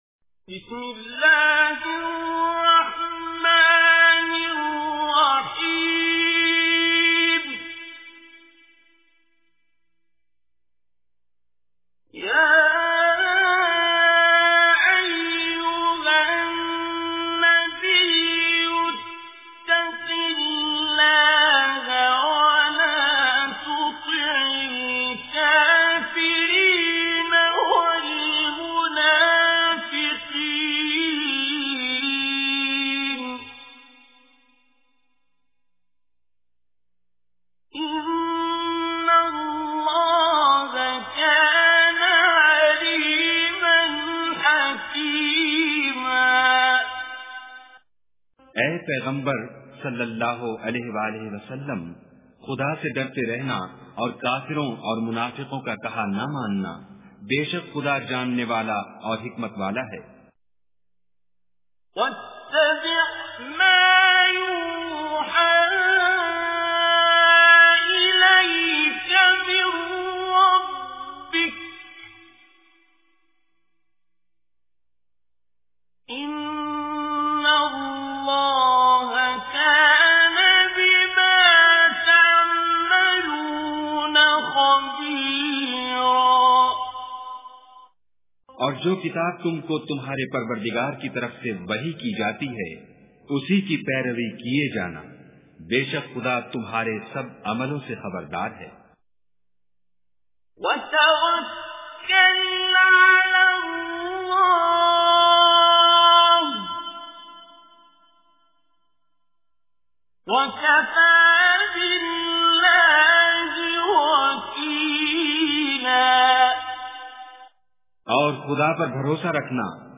Surah Al-Ahzab Recitation with Urdu Translation
Listen online and download Tilawat / Recitation of Surah Al Ahzab in the voice of Qari Abdul Basit As Samad.